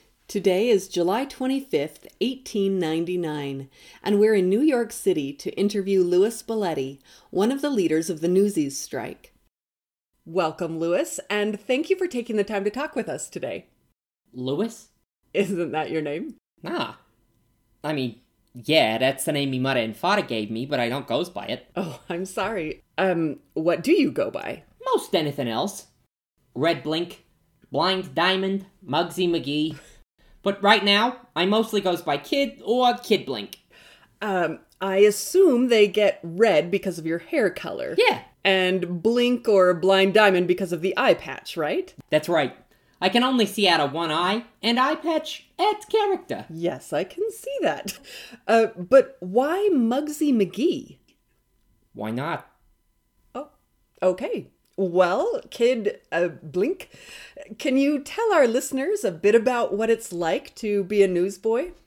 Everyone is Important: “Kid Blink” (Dramatized Interview & Discussion Guide)
A dramatized interview with Louis “Kid Blink” Balletti, one of the leaders of the New York newsboys strike. How did a bunch of kids take on the some of the most powerful men and America, and why was it so important?